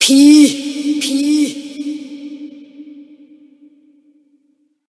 Cri de Pikachu Gigamax dans Pokémon HOME.
Cri_0025_Gigamax_HOME.ogg